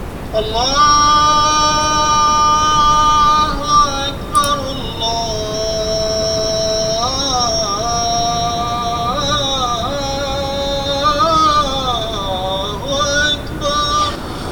Här ljuder det första arabiska böneutropet över den småländska nejden » Samnytt
På fredagen var det premiär för de kontroversiella muslimska böneutropen från moskén i Växjö. Ur en skorrande högtalare ljöd för första gången ”Allahu-akbar-la-ah-ah-uh-ah…” över den småländska nejden.